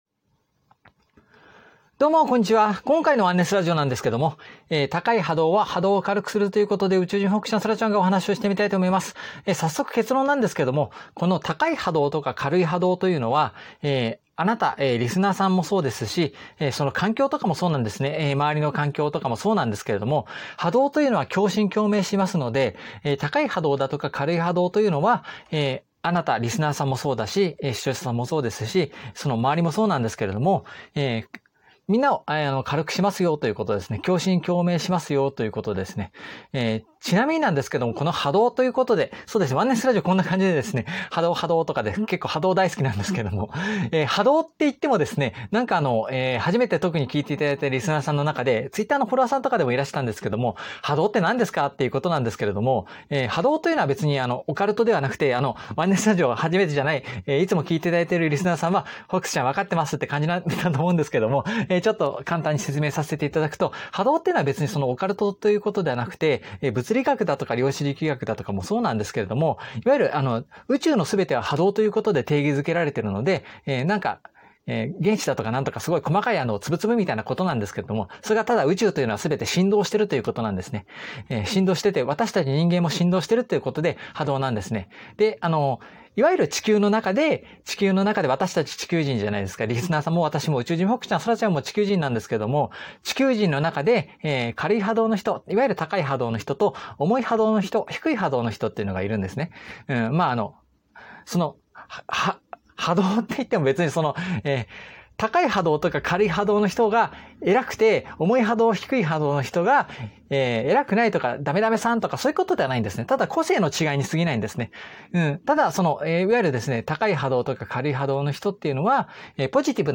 ゆるーい雑談ラジオ。